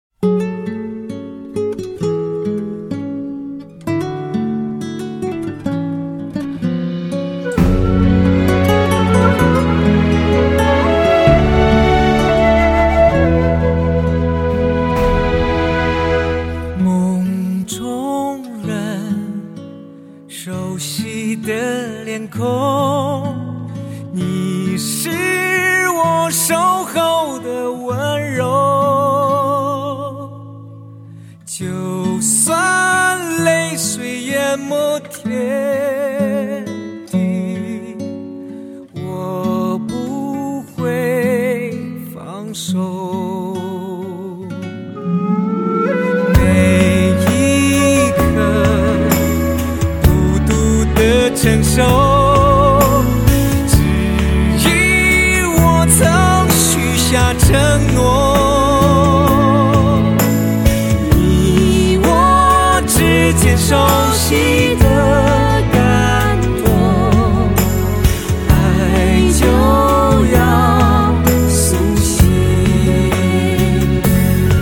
• Качество: 161, Stereo
гитара
мелодичные
спокойные
восточные
фолк
романтические
приятная мелодия
Красивая китайская песня